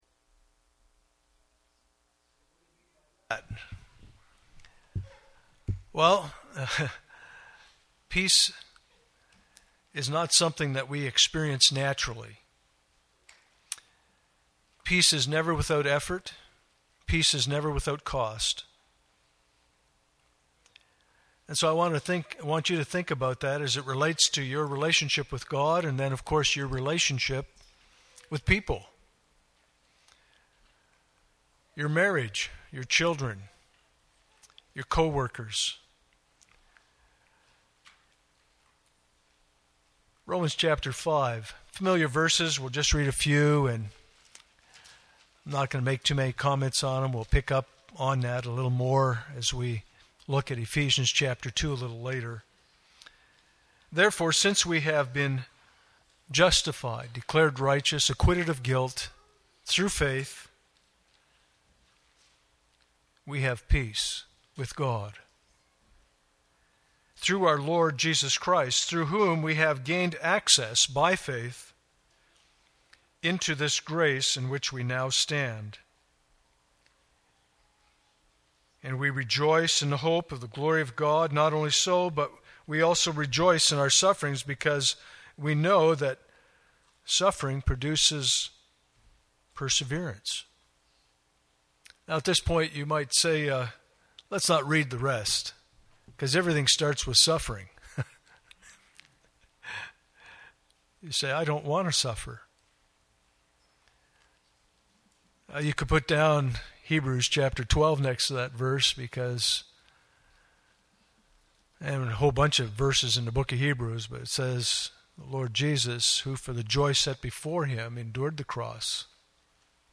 Communion Service
Communion Passage: Ephesians 2:11-18 Service Type: Sunday Morning « 2018 Bible Conference